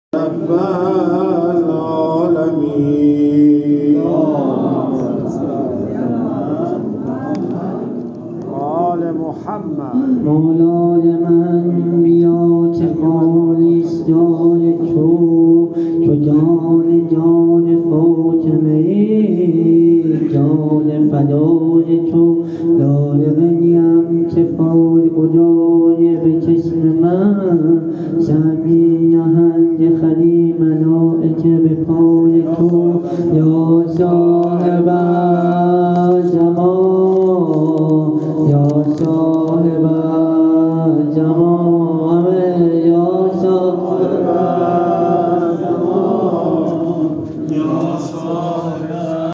مدح خوانی امام زمان
مسجد حاج حسن خرقانی